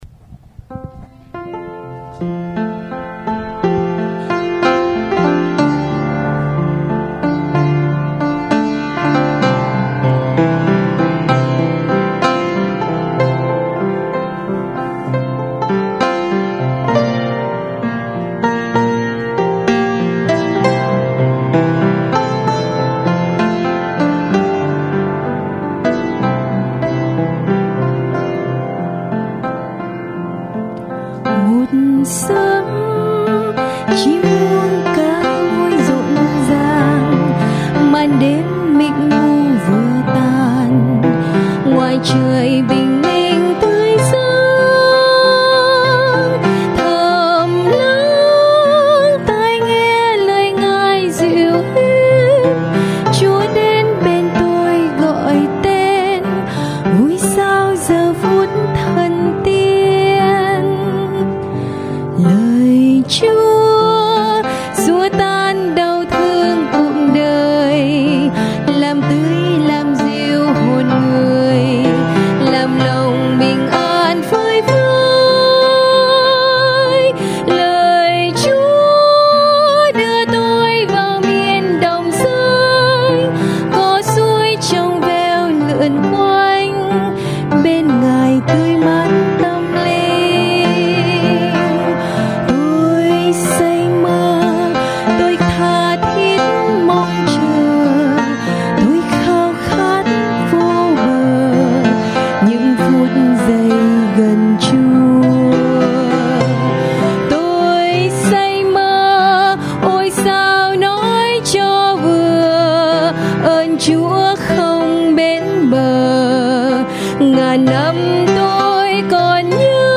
Music/Nhạc